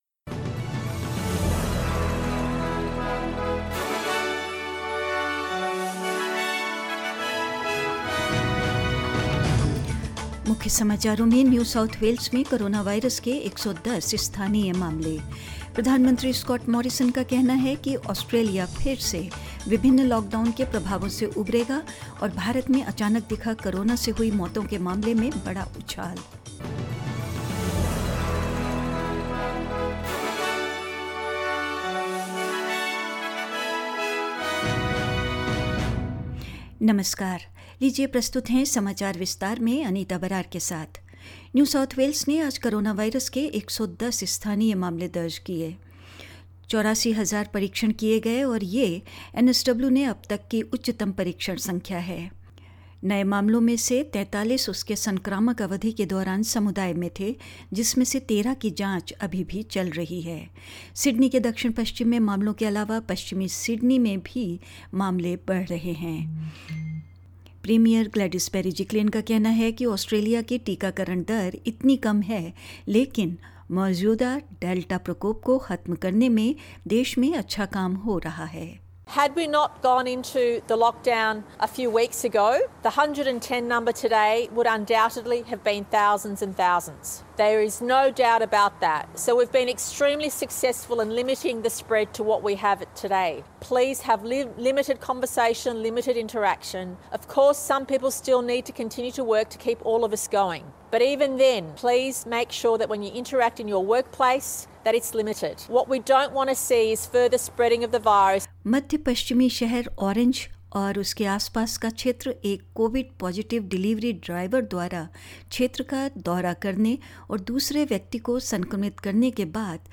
In this latest SBS Hindi News bulletin of Australia and India: New South Wales continues to record high numbers of COVID-19 cases; Prime Minister Scott Morrison says Australia will once more recover from the impacts of coronavirus outbreaks; India wins second ODI against Sri Lanka and more news